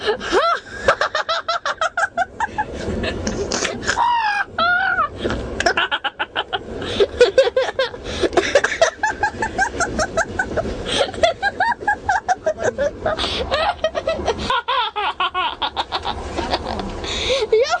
Звуки мужского смеха
Интересный смех